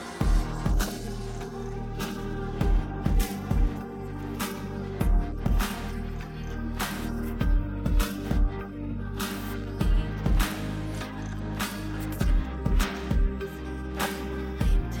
【ステレオソングからボーカルを削除】